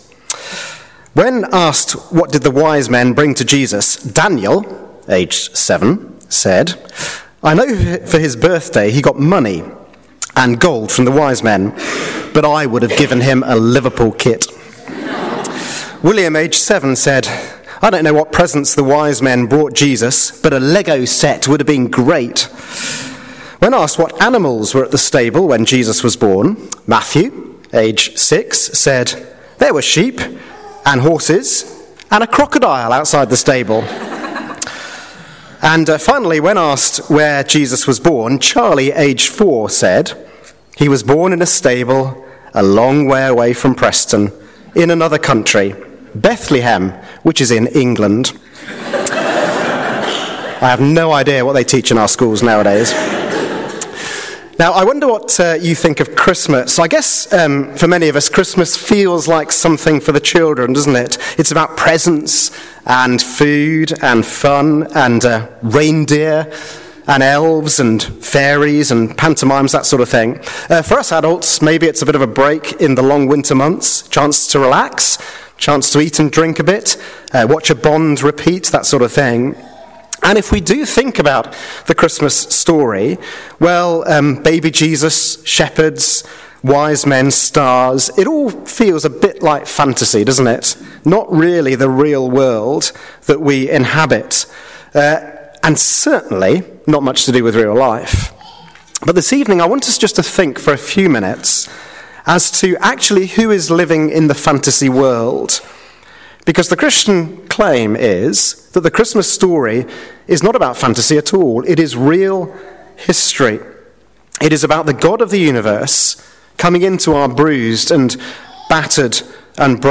Carol Service Talk